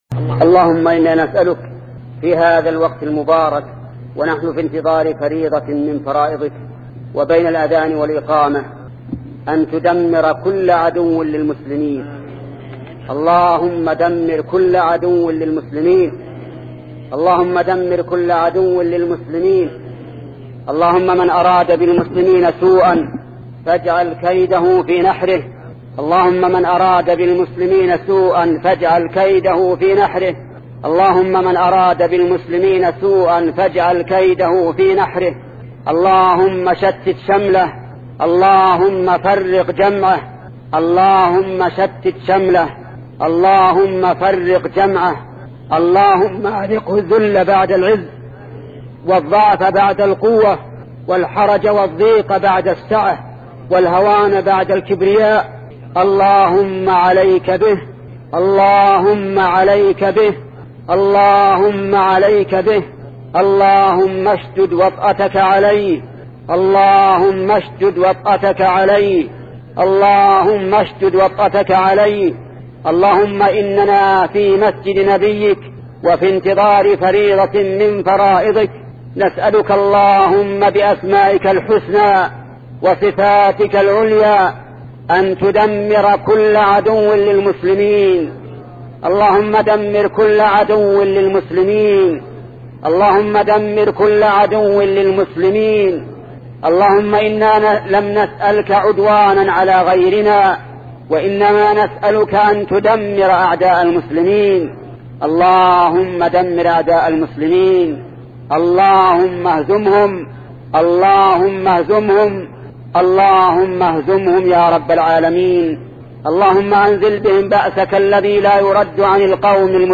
دعاء الشيخ ابن عثيمين على من أراد المسلمين بسوء - الشيخ محمد بن صالح العثيمين